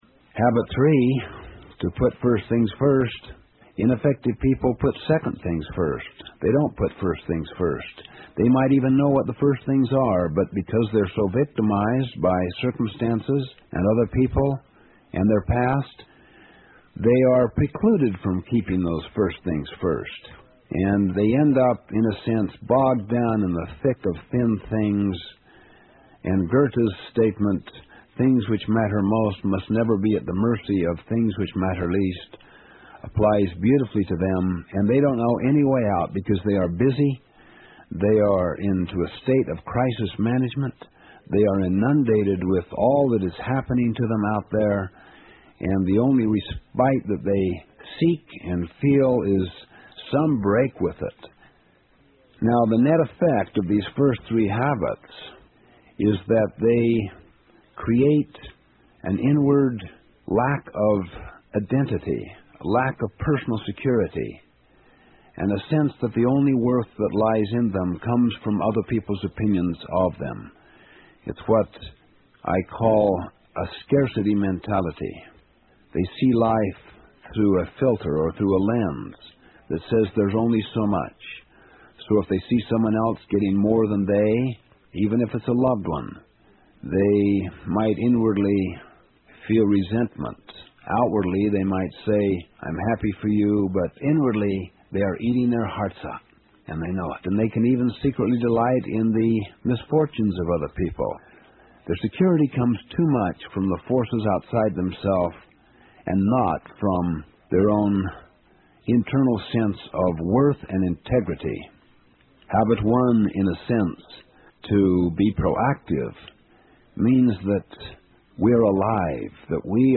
有声畅销书：与成功有约04 听力文件下载—在线英语听力室